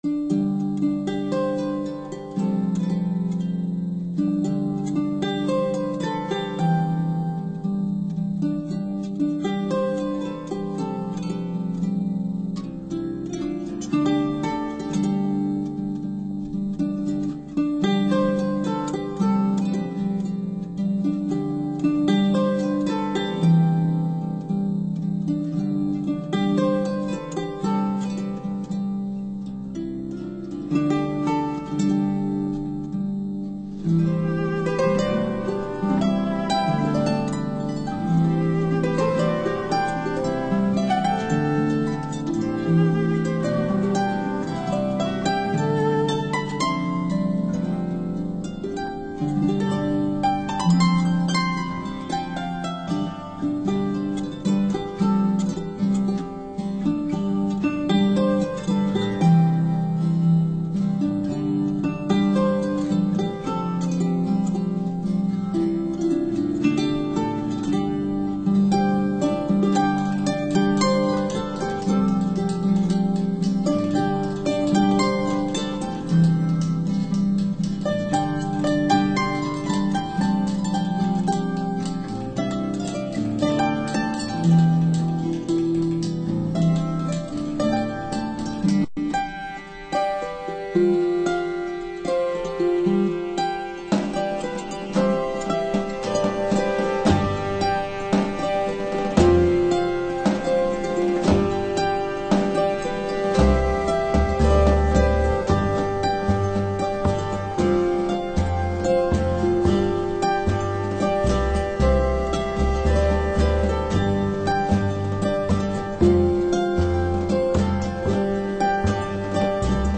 轻松的旋律，明快的节奏，混合着若有若无的咖啡香味在空气中回旋。